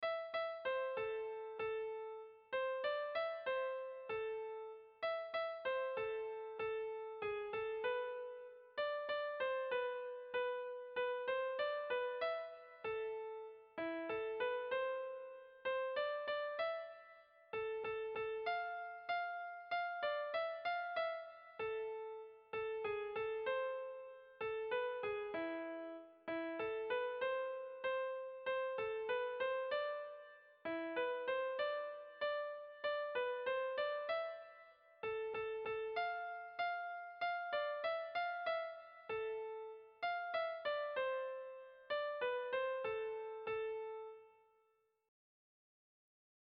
Air de bertsos - Voir fiche   Pour savoir plus sur cette section
Sentimenduzkoa
Hamarreko handia (hg) / Bost puntuko handia (ip)
ABDEF